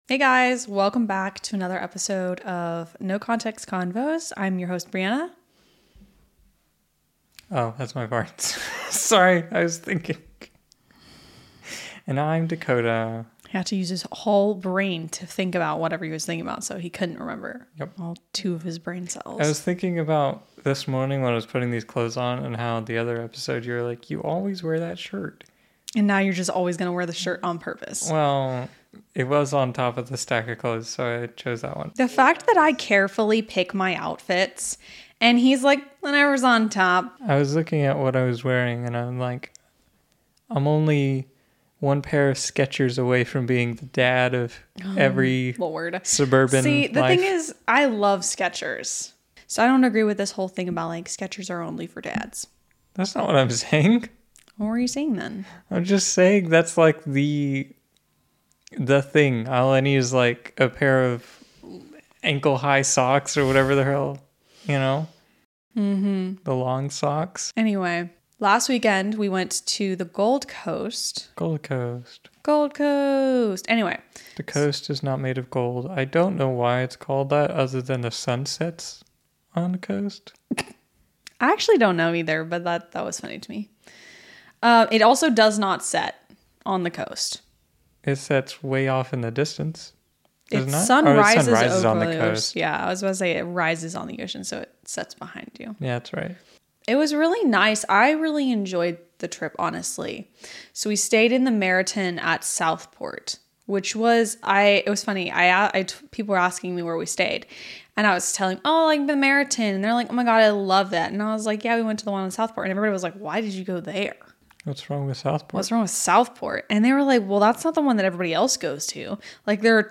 Play Rate Apps Listened List Bookmark Share Get this podcast via API From The Podcast No Context Convos 1 Laugh along with this witty husband and wife duo as they banter and riff on all aspects of life, relationships, current events, and more on No Context Convos.